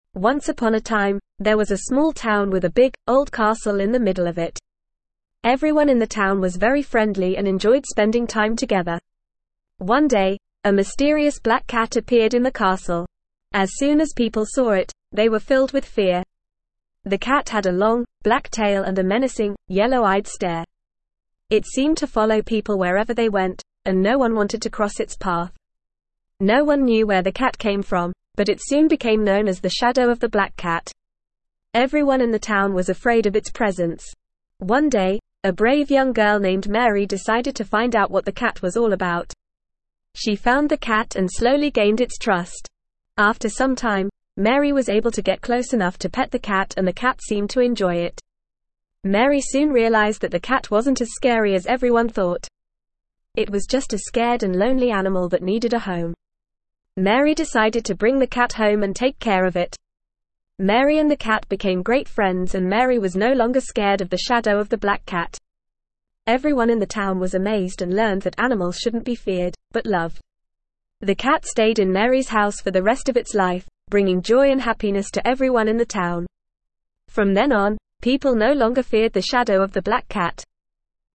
Normal
ESL-Short-Stories-for-Kids-NORMAL-reading-The-Shadow-of-the-Black-Cat.mp3